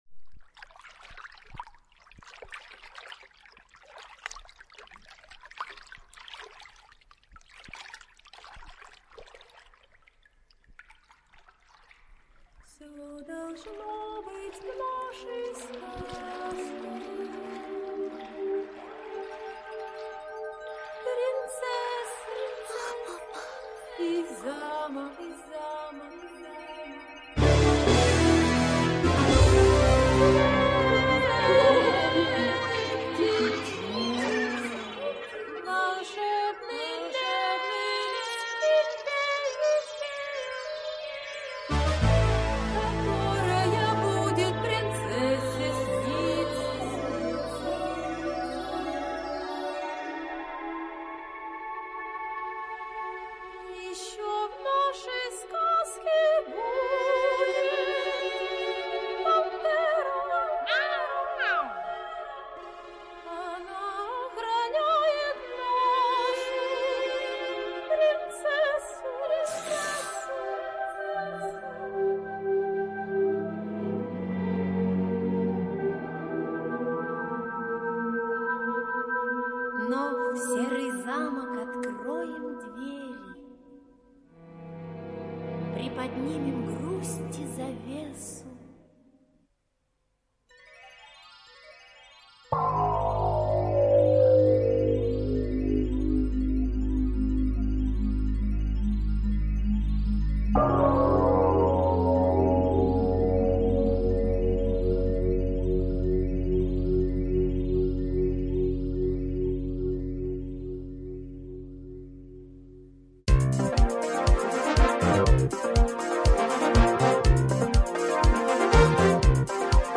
Тайна синей пантеры - аудиосказка - слушать онлайн